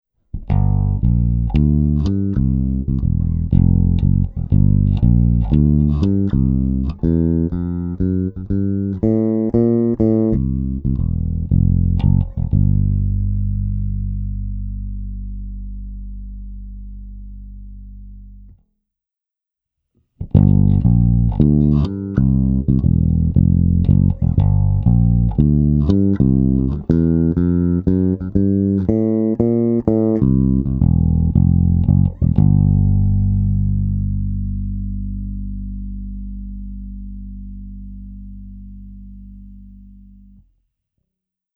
SR dry–wet (nejdříve bez, potom s kompresorem)